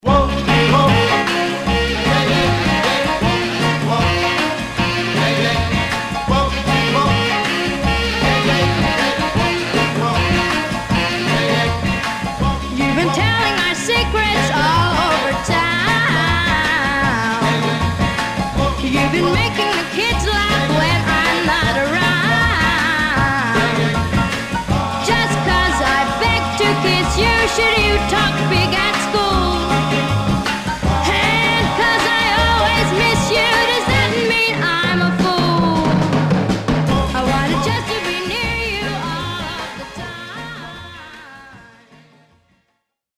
Some surface noise/wear Stereo/mono Mono
Teen